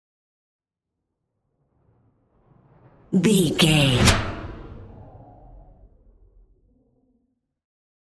Whoosh super fast
Sound Effects
Fast
futuristic
whoosh